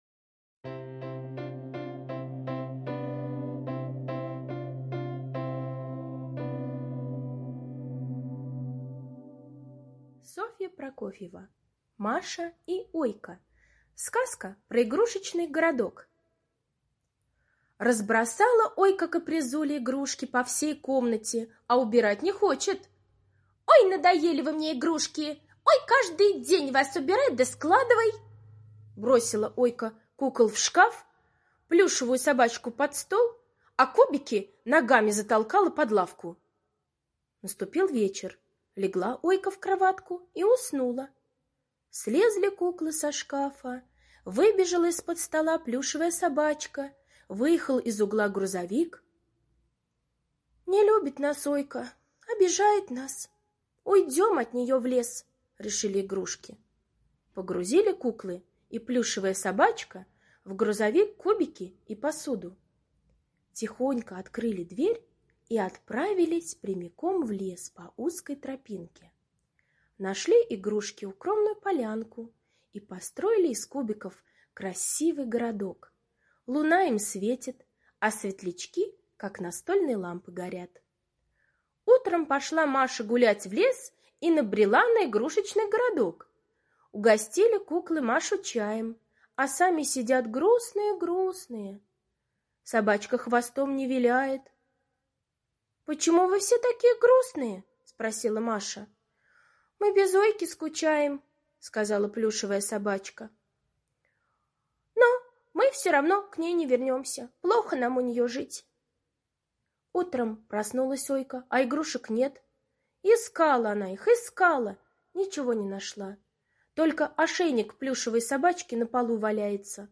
Сказка про игрушечный городок - аудиосказка Прокофьевой С. История о том, как Ойка не захотела убрать игрушки, и они убежали от нее в лес.